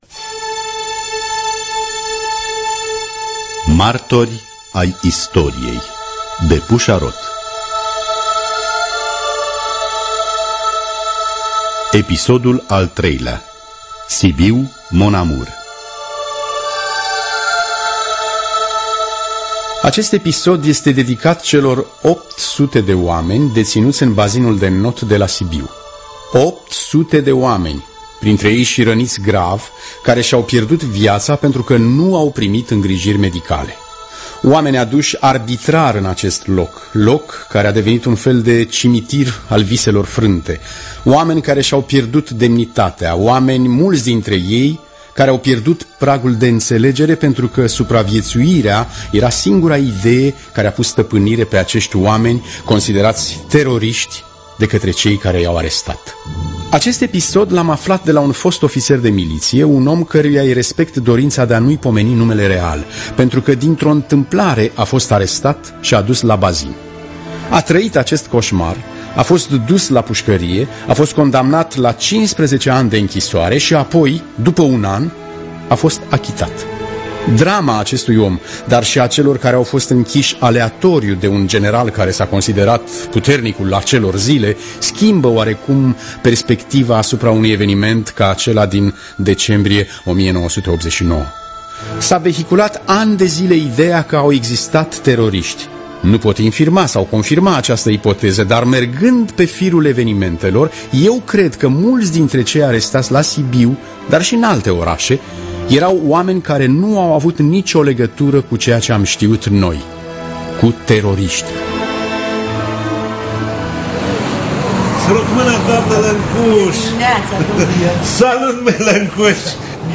Pusa Roth – Martori Ai Istoriei (2011) – Episodul 3 – Teatru Radiofonic Online